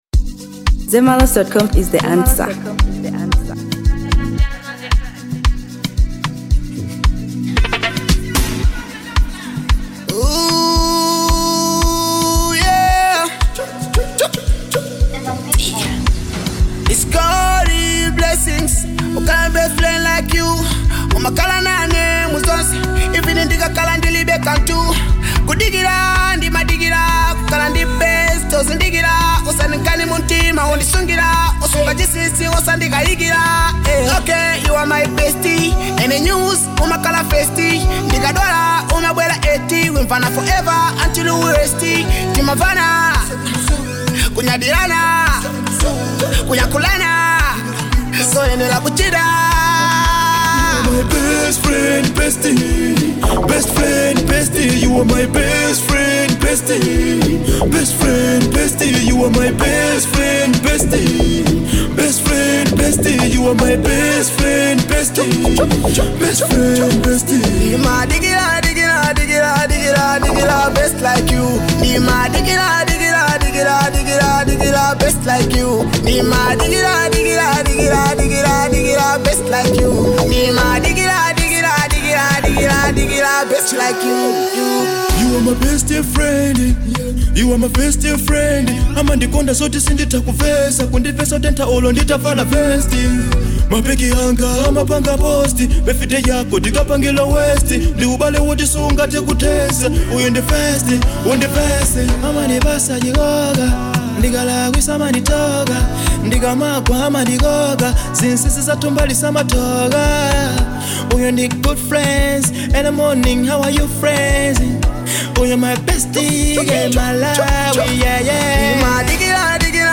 Kwaito|Afrobeats|Amapiano|Dancehall • 2025-07-12